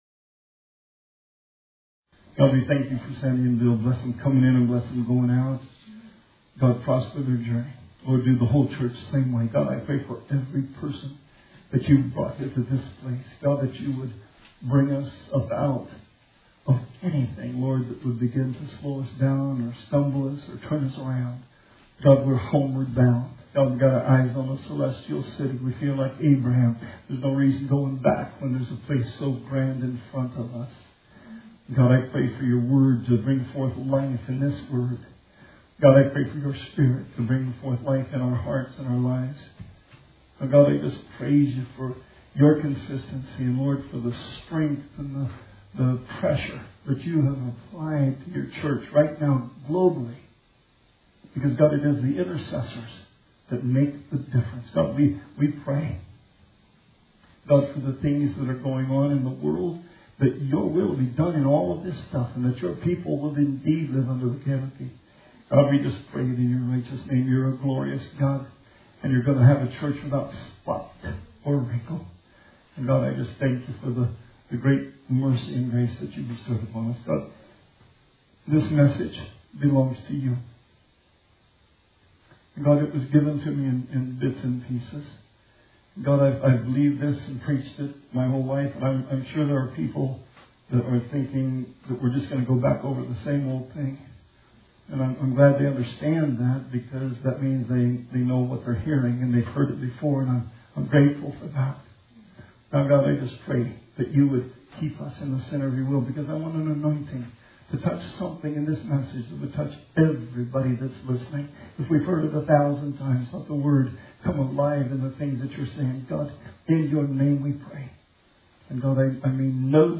Sermon 4/19/20